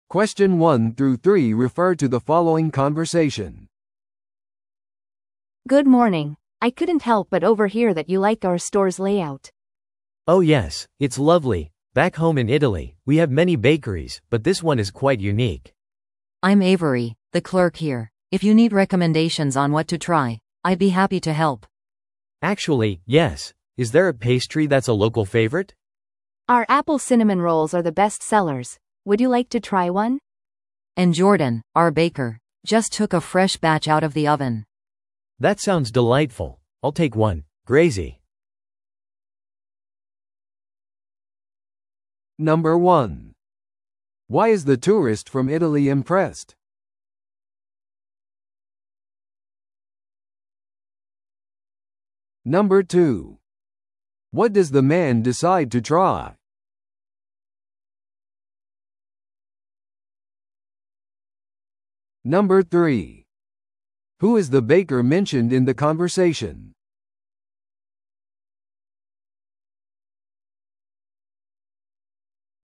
TOEICⓇ対策 Part 3｜ベーカリーの一押し商品についての会話 – 音声付き No.175